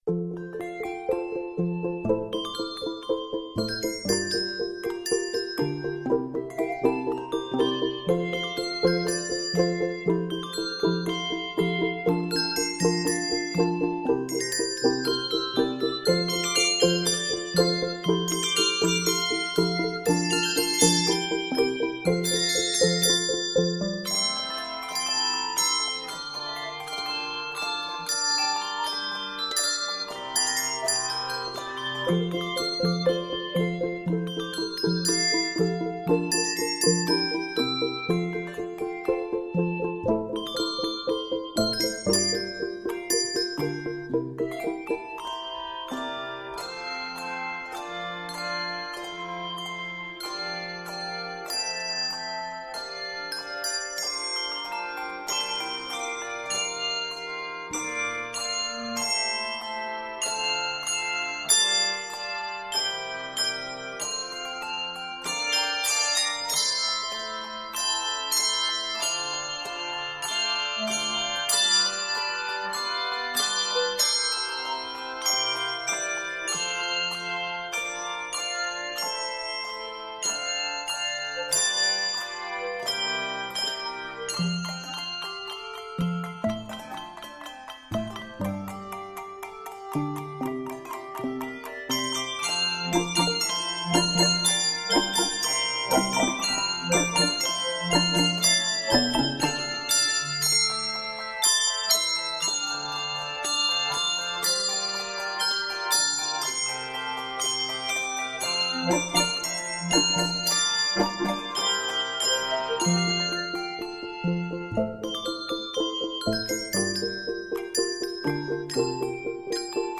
Octaves: 3-6